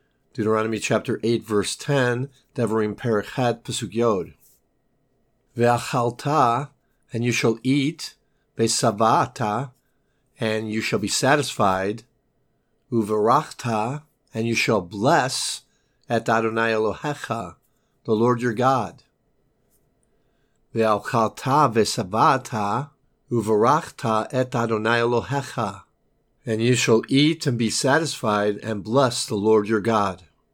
Deut. 10:8a Hebrew Lesson